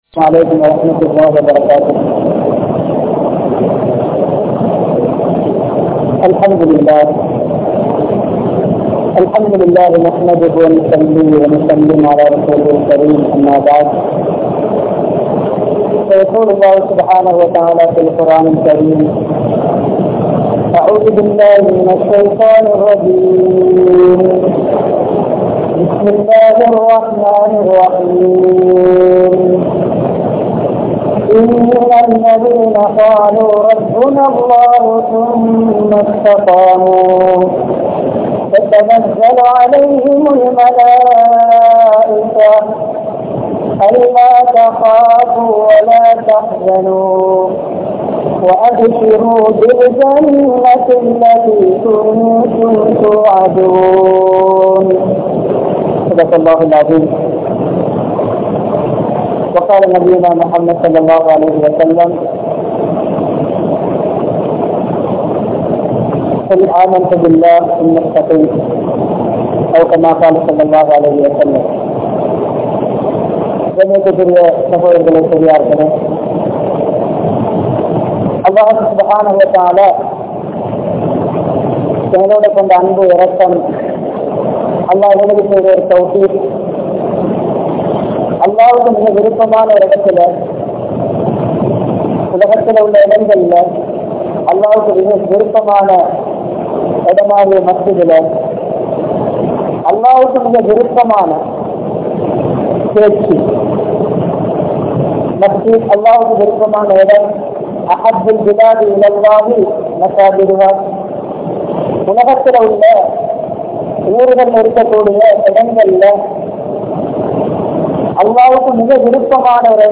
Isthiqaamth Entraal Enna? (இஸ்திகாமத் என்றால் என்ன?) | Audio Bayans | All Ceylon Muslim Youth Community | Addalaichenai
Colombo 03, Kollupitty Jumua Masjith